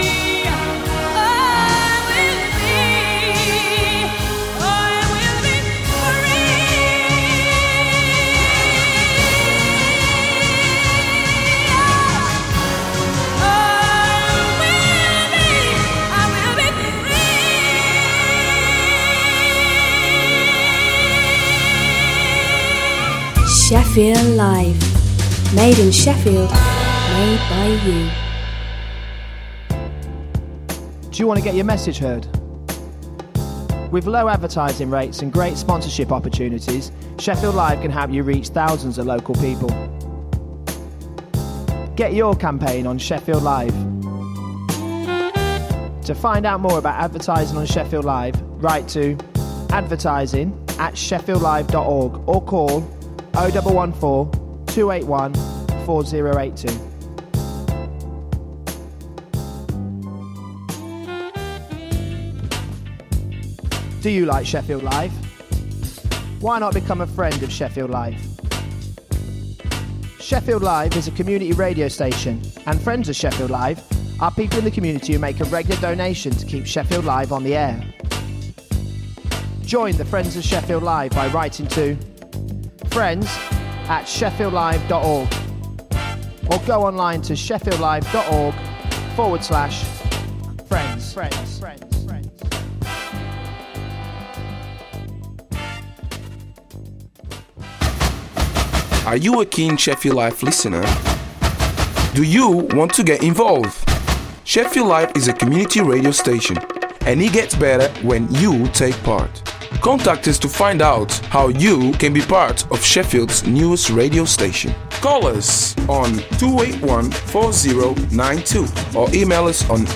A programme that introduces all different styles of Latin music from its roots and at the same time inform the audience of the latest issues in Latin America, also interviews with artists visiting the city (not necessarily South American).